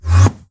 Sound / Minecraft / mob / endermen / portal2.ogg
portal2.ogg